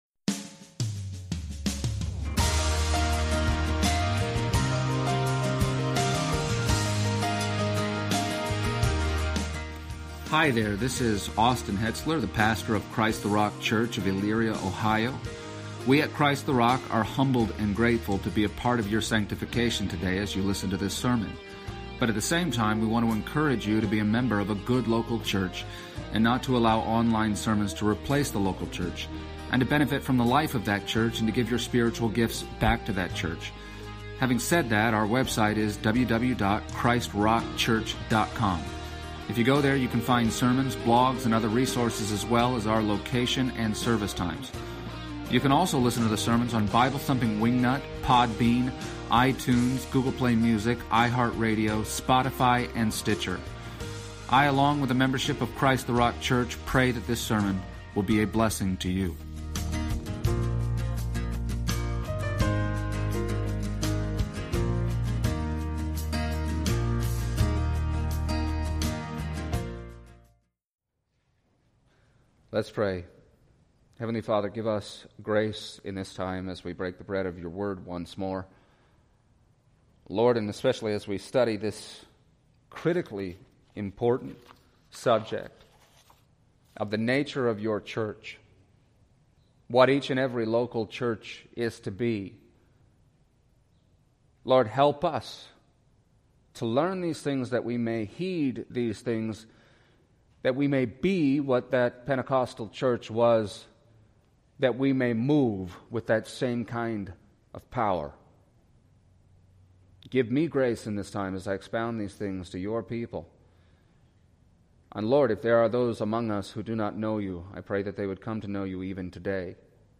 Passage: Acts 2:37-47 Service Type: Sunday Morning